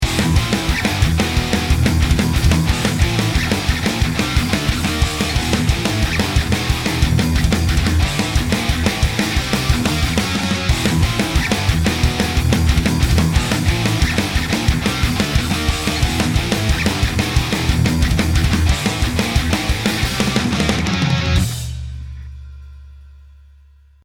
Here’s the track we’re going to work on, a fast trash metal song. Listen closely to the guitars as they’re what we’ll be focusing on:
Although we have two different guitar tracks in our example song it’s actually only one part which has been recorded twice and then panned hard left and right respectively to achieve a big and wide sound.
StripV3-G-Character-Thrash-Metal-DRY.mp3